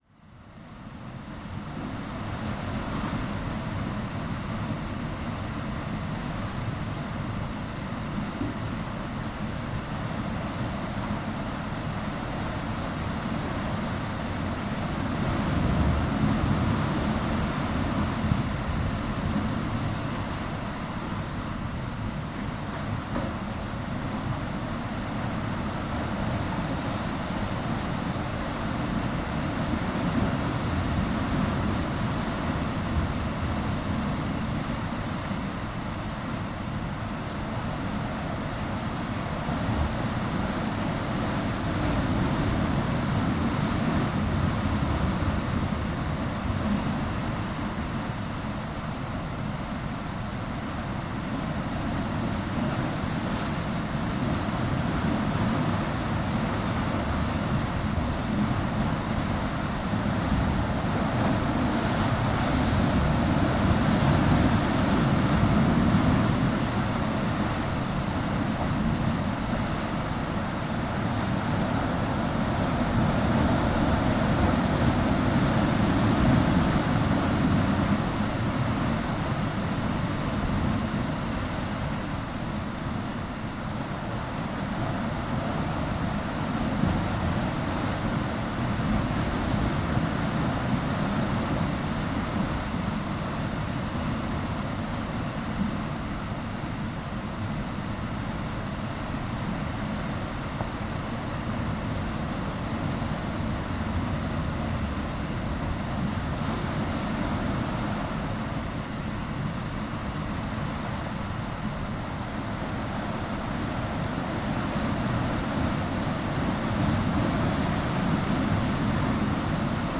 In mouth of Rocky Valley, near Tintagel, Cornwall, 1 Sound Effect — Free Download | Funny Sound Effects
Recording made on 8th September 2012 with a Sony PCM-M10 on a Hama m